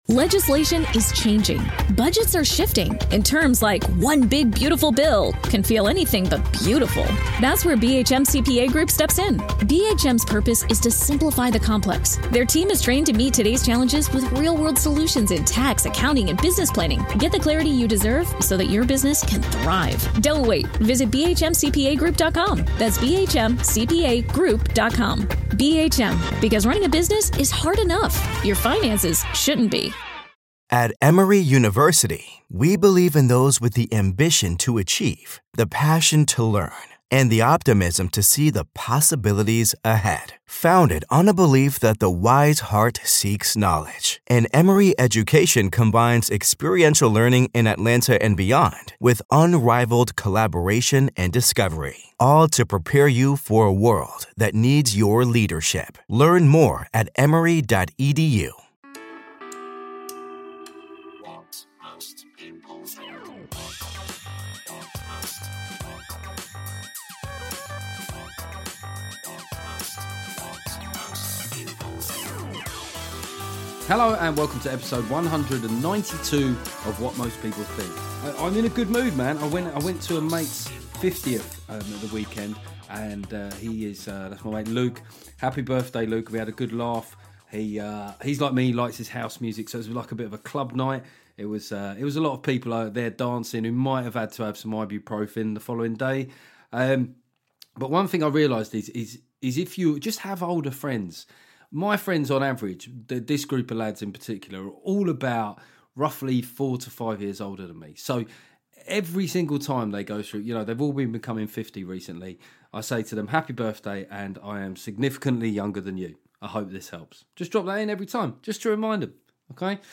After a quick catch-up from some mental drugs announcements from the two main parties, I speak to proper stand-up Zoe Lyons. We have a good old chin-wag about how comedy is going and whether social media comics have what it takes for live. Plus Zoe, as a left leaning comic, shares her surprise at how some of our colleagues reacted to Brexit in their acts.